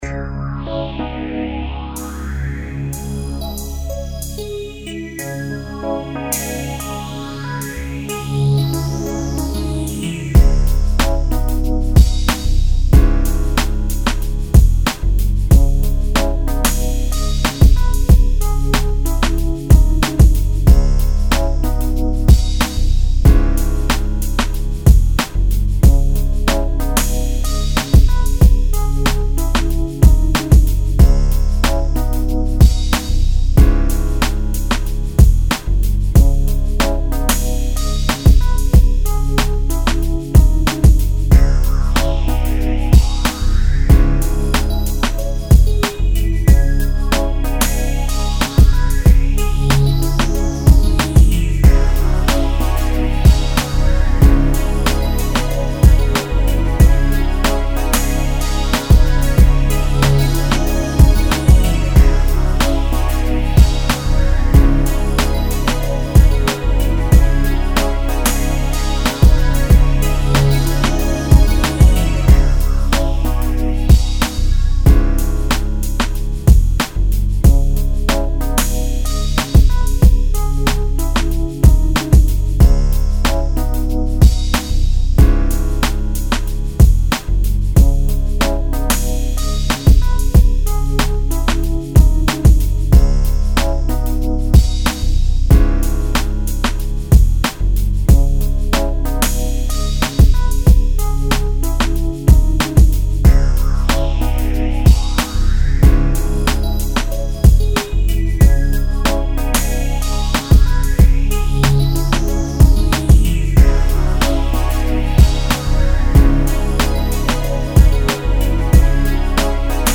93 BPM. Laid back beat, electric pianos and pads.